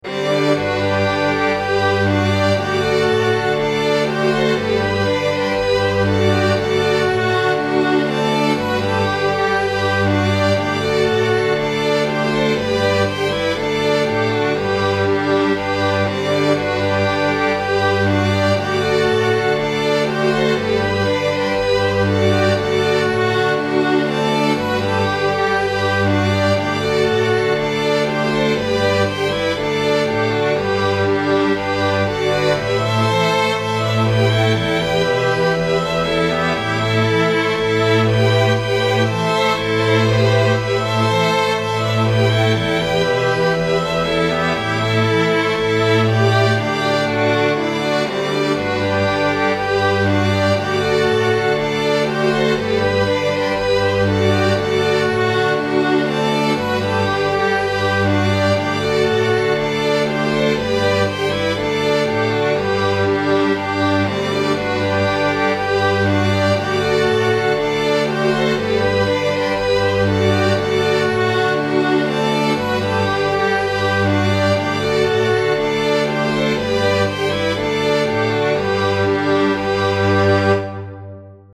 it's in A-B-A format, although the B part isn't as fleshed out as I would like.  I wrote this for a string quartet (1st and 2nd violin, viola, and cello), and it works well enough.  Perhaps I'm a harsh critic, but violin 1 has the melody and at time it seems to be a little muddied by the other parts.
To me it sounds so "Masterpiece Theater"  Sweet.
* I did hear a bit of the melody getting a little drowned, I still like it.